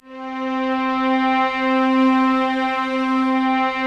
Index of /90_sSampleCDs/Optical Media International - Sonic Images Library/SI1_Swell String/SI1_Mello Swell